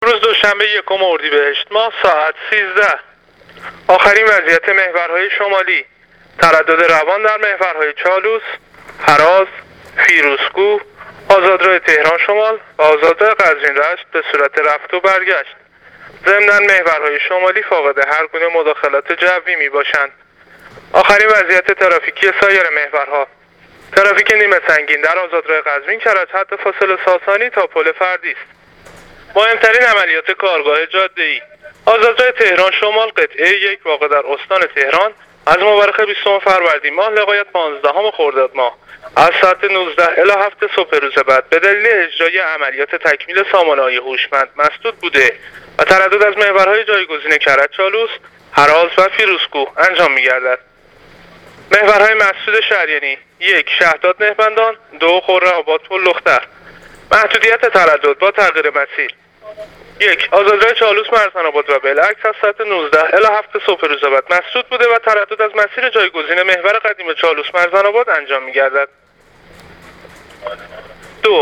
گزارش رادیو اینترنتی از آخرین وضعیت ترافیکی جاده‌ها تا ساعت ۱۳ اول اردیبشهت ۱۳۹۹